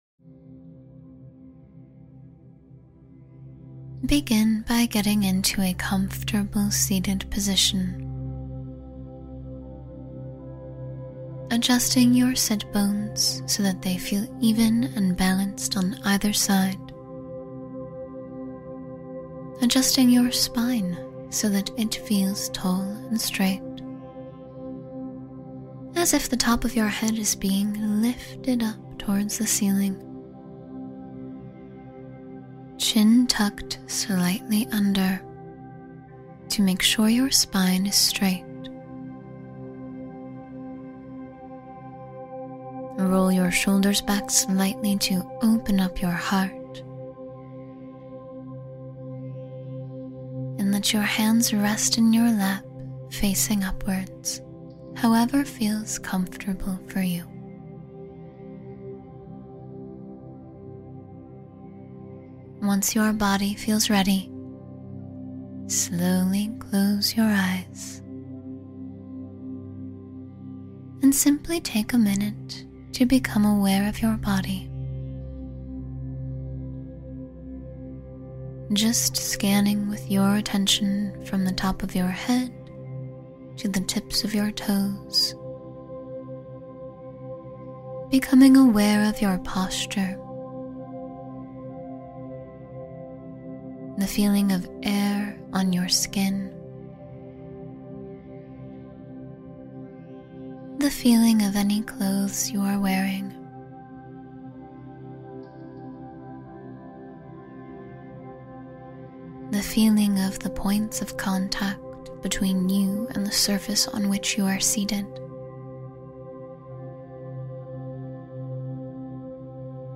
Ease Your Mind and Release Tension — A Guided Meditation for Calm